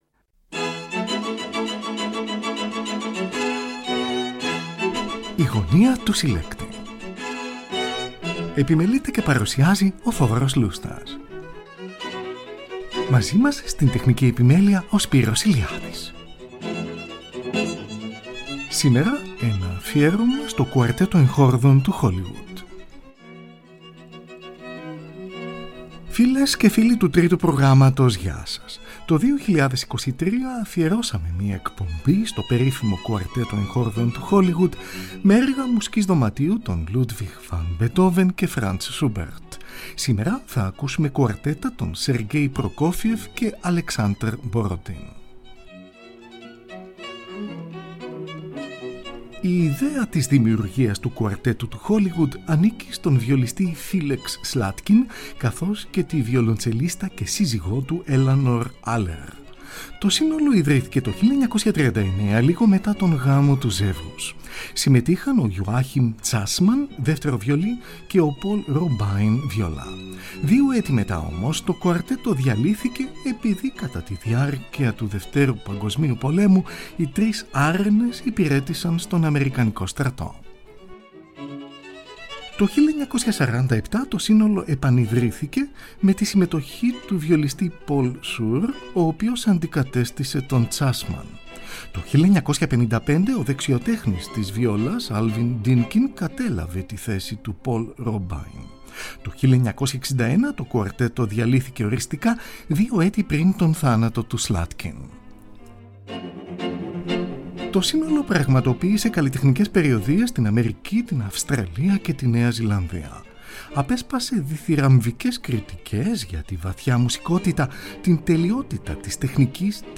πρώτο βιολί
δεύτερο βιολί
βιόλα
βιολοντσέλο
Ιστορικες Ηχογραφησεις